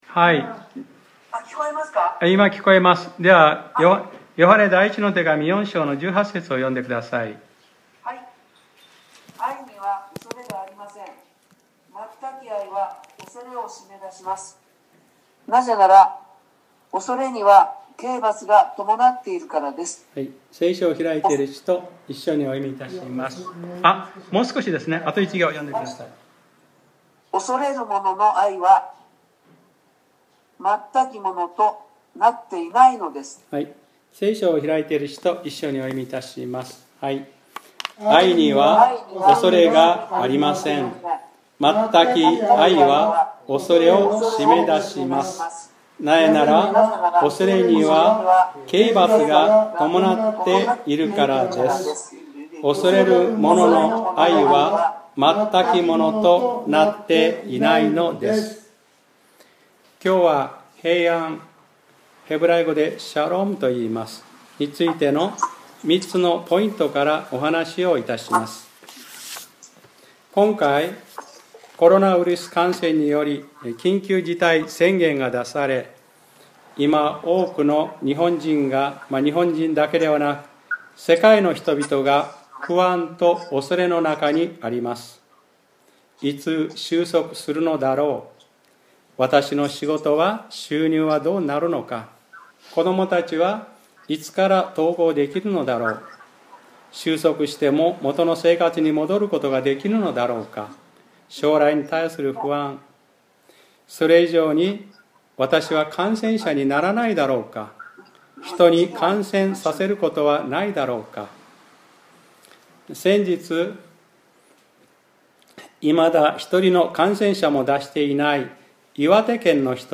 2020年05月03日（日）礼拝説教『平安』